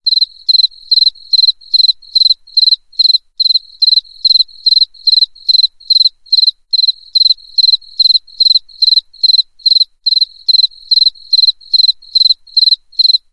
PLAY grilo.mp3
grilo.mp3